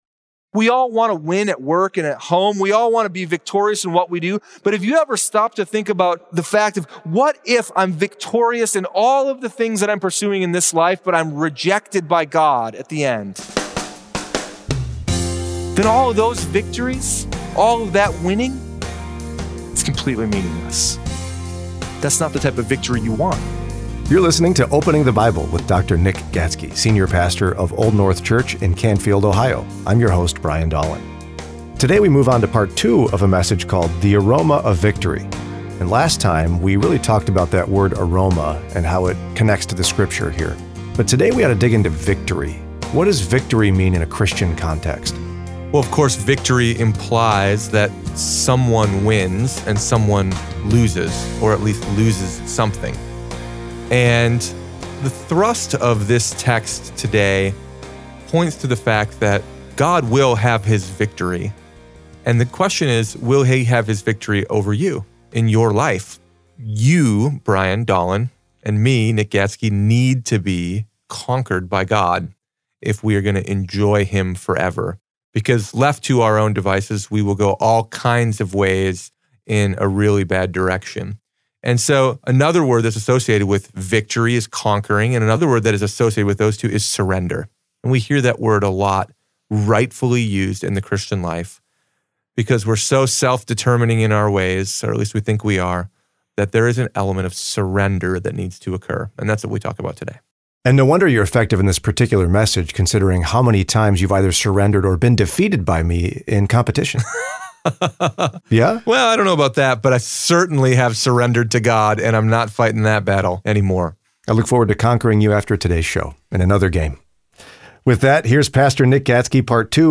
Sermon Library – Old North Church